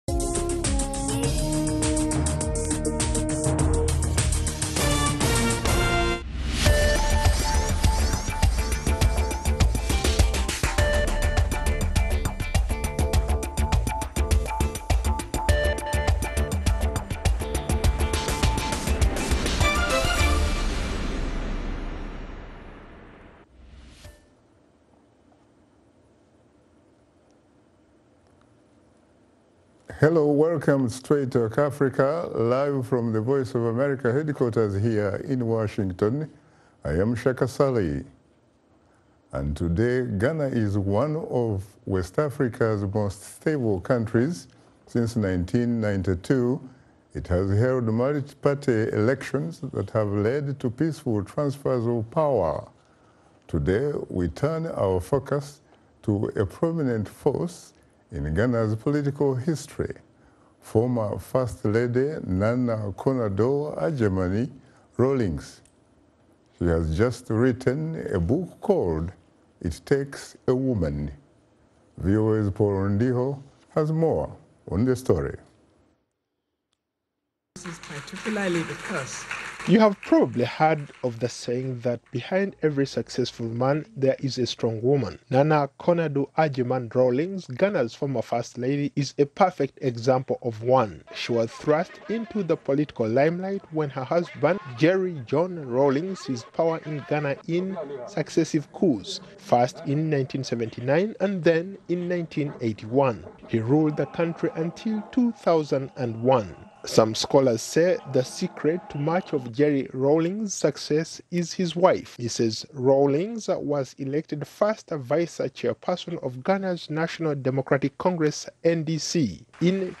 A One on One Interview